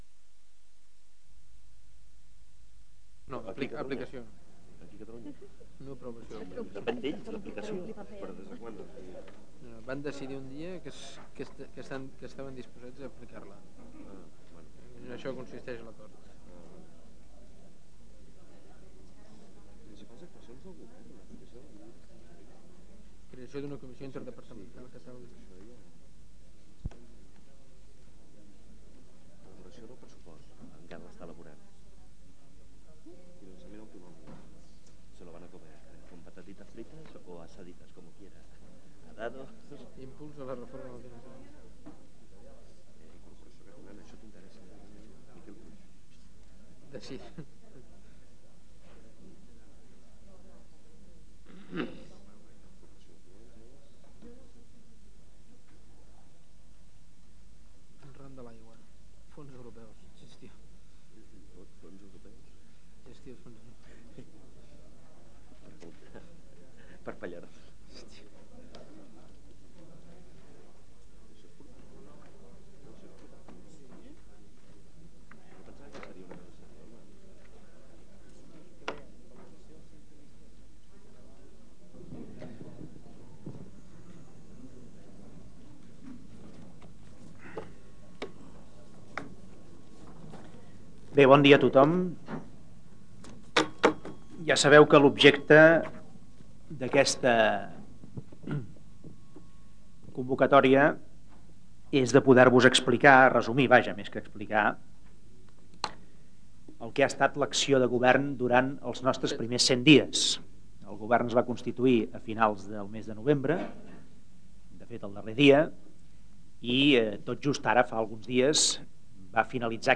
Roda de premsa
Aquest document conté el text POL4, una "roda de premsa" que forma part del Corpus Oral de Registres (COR).